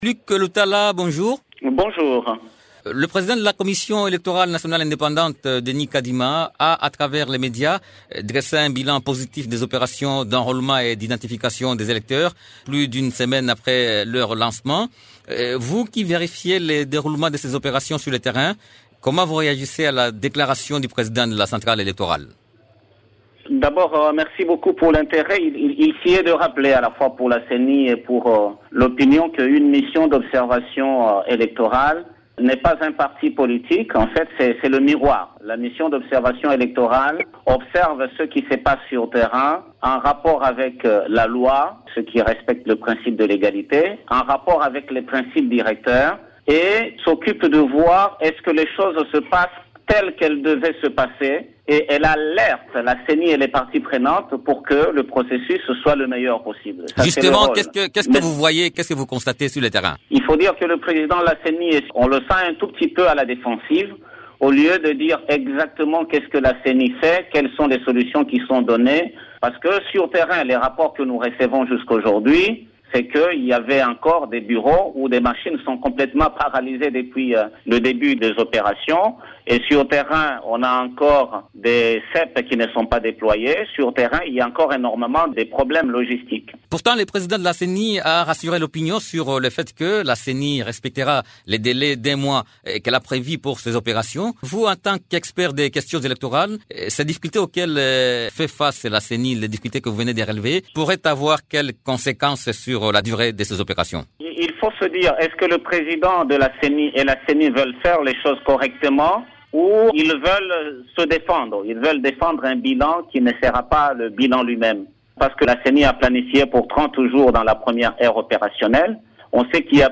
Il s’entretient avec